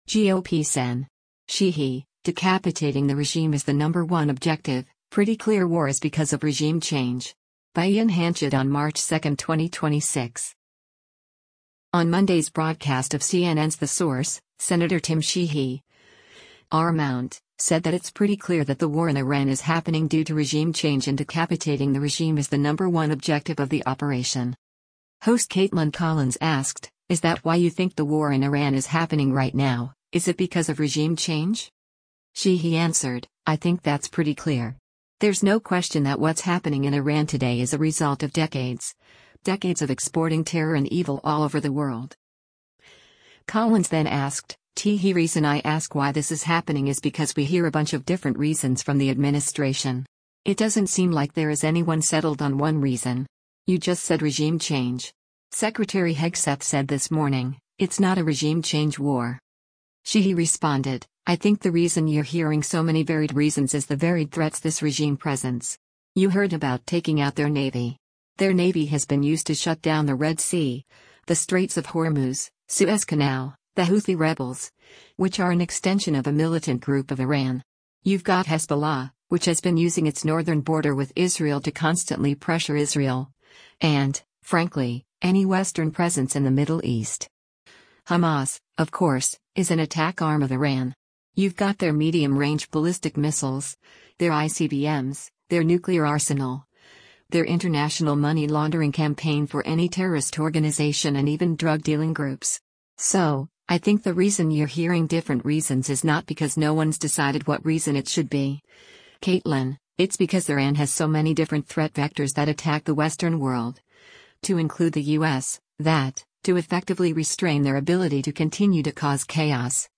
On Monday’s broadcast of CNN’s “The Source,” Sen. Tim Sheehy (R-MT) said that it’s “pretty clear” that the war in Iran is happening due to regime change and “decapitating the regime is the number one objective” of the operation.
Host Kaitlan Collins asked, “Is that why you think the war in Iran is happening right now, is it because of regime change?”